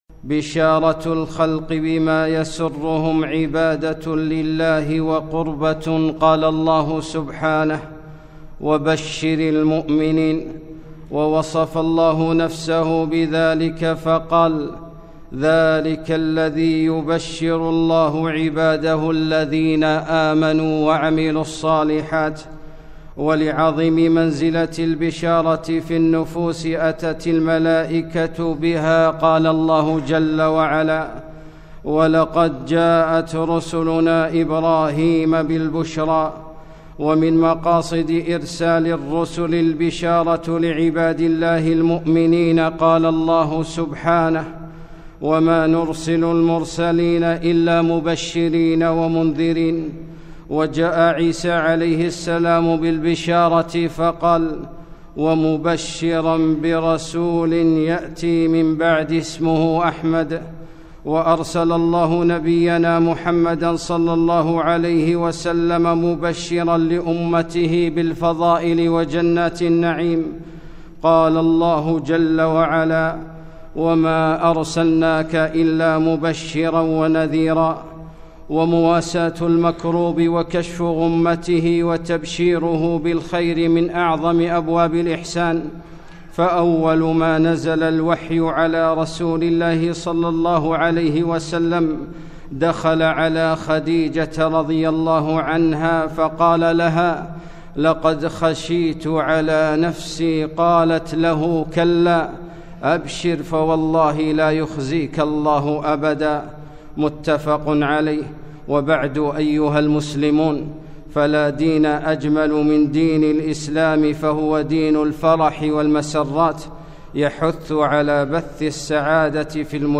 خطبة - بشر هذه الأمة - دروس الكويت